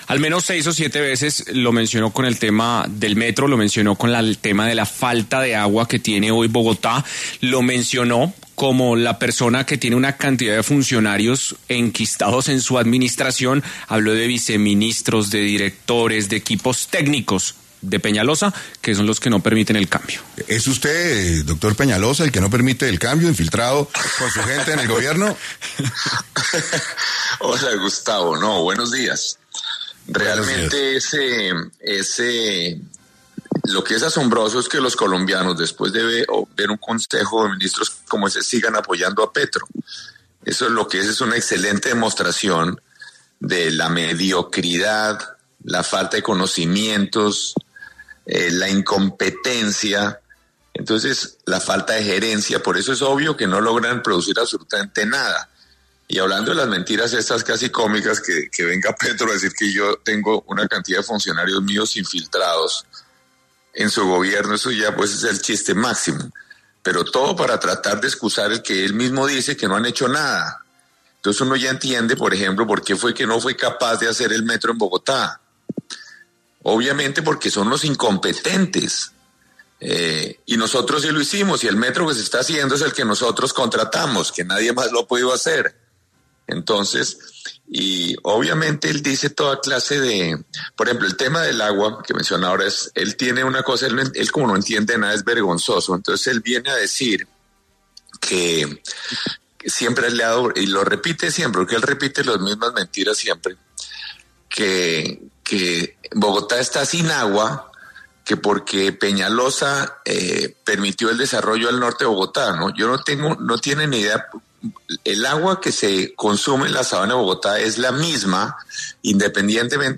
Enrique Peñalosa, exalcalde de Bogotá, estuvo en 6AM para hablar de temas coyunturales que afectan a Bogotá, principalmente del Metro.
En este orden de ideas, Enrique Peñalosa, pasó por los micrófonos de 6AM para exponer sus puntos de vista frente a esta situación.